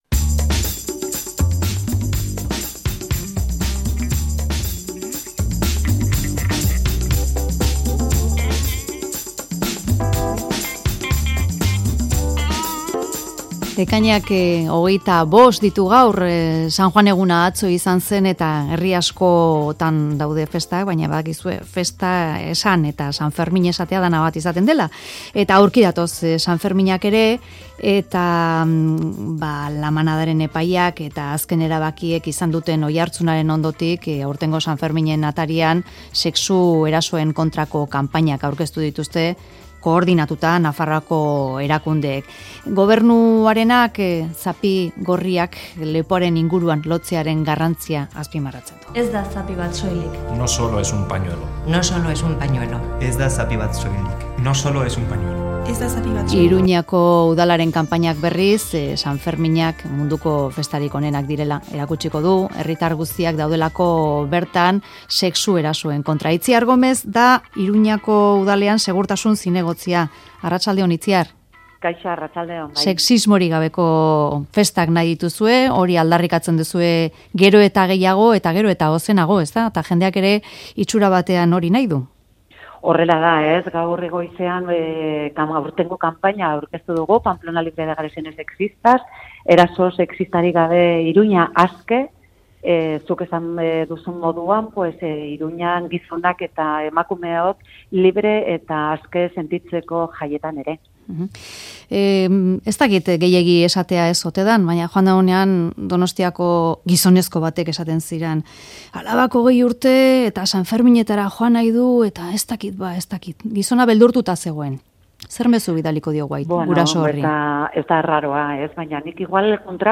Audioa: Itziar Gomez Iruñeko Udaleko Segurtasun zinegotziak aurkeztu du Mezularian sexu erasoen aurka Sanferminetarako prestatu duten kanpaina: 'Eraso sexistarik gabe, Iruñea aske'.